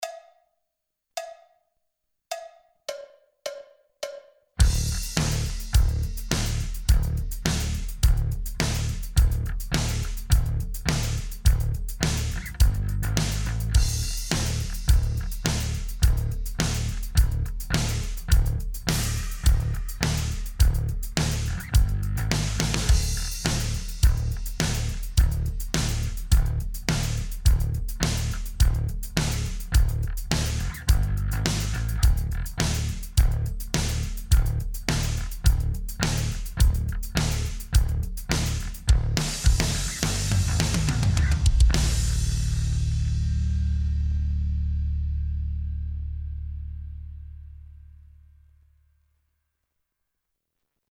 Riffi tausta